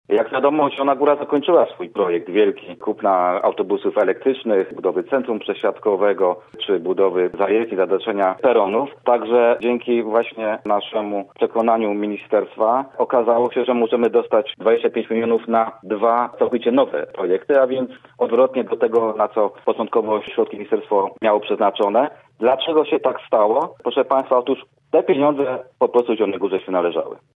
Chodzi o dokończenie projektów już realizowanych w miastach wojewódzkich, jednak Zielona Góra otrzyma pieniądze wyjątkowo na dwa nowe projekty, tłumaczył w programie Sobota po 9 wiceprezydent miasta Krzysztof Kaliszuk: